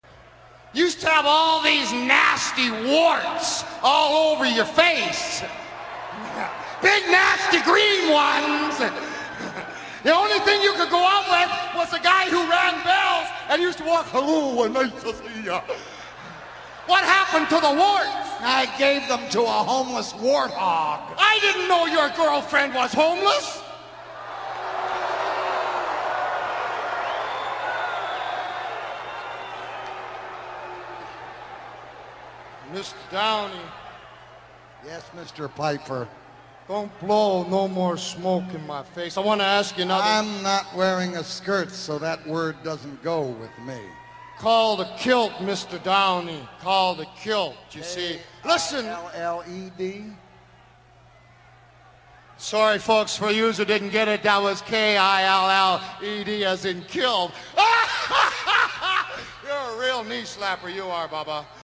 So Mort blows smoke in Piper’s face as the two have a verbal sparring contest that the crowd no-sells completely.